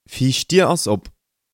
frontdoor_open.mp3